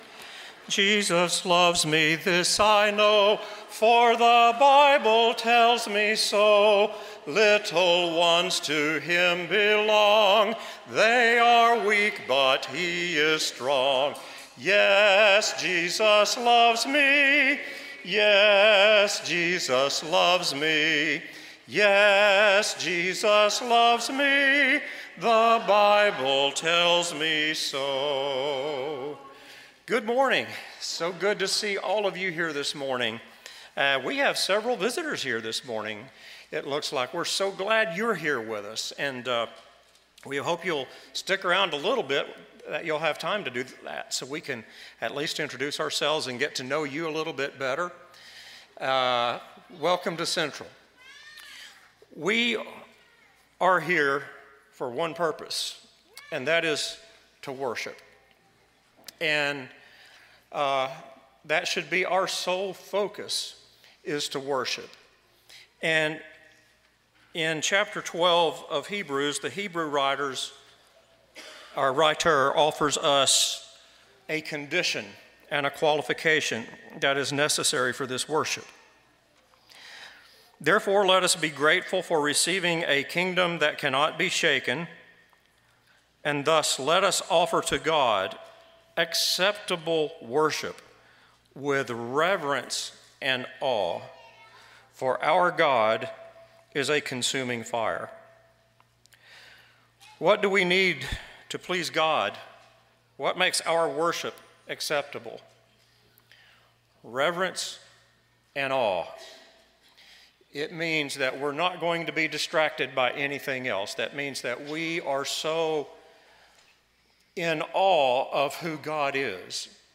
Psalm 18:3, English Standard Version Series: Sunday AM Service